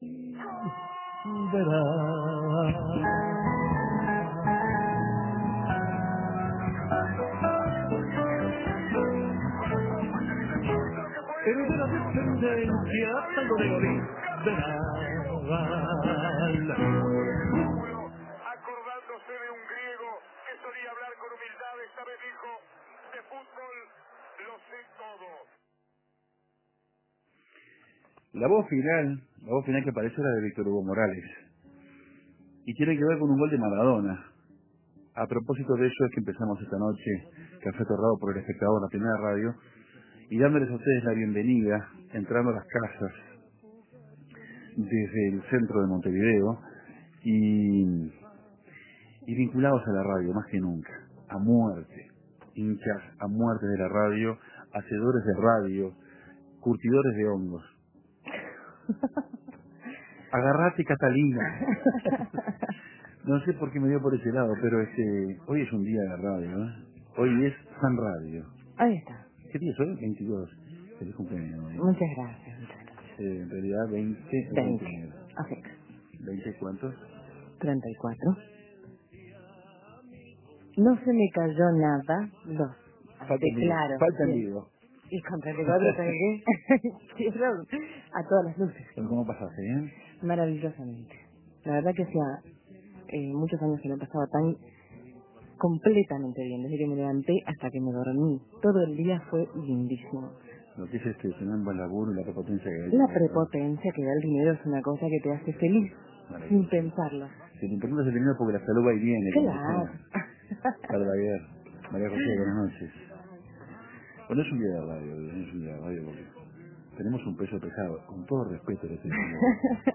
La emoción a flor de piel.